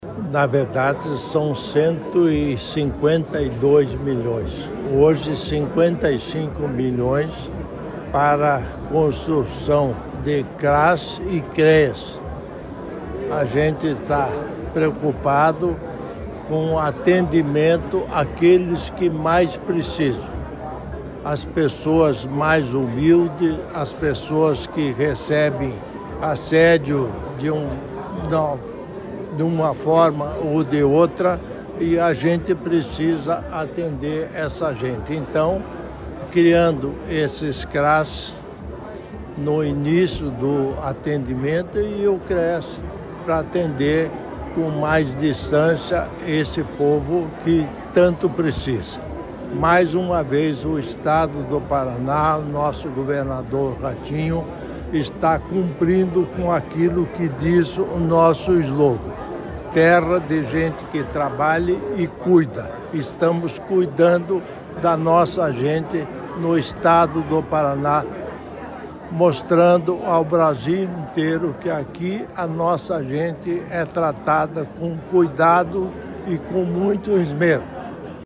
Sonora do governador em exercício Darci Piana sobre o repasse aos municípios para novos Cras e Creas